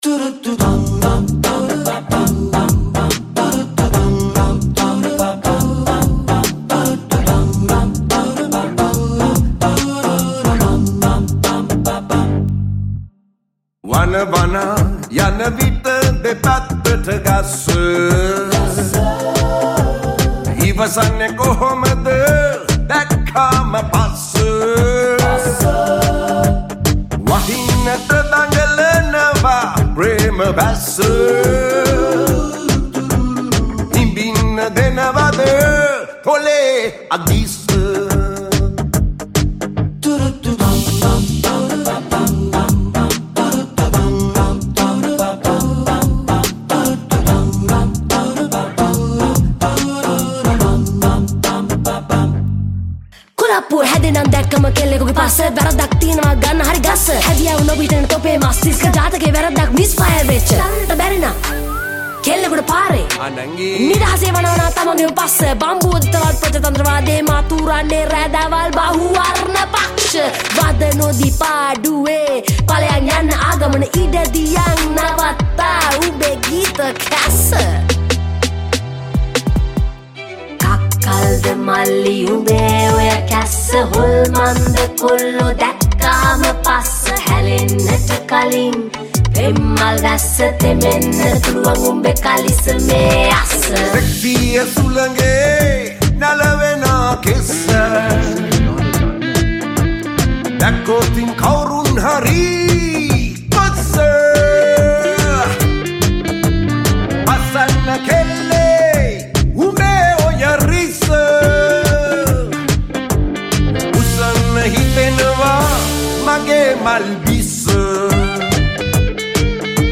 Rap
Bass
Guitar
Melodica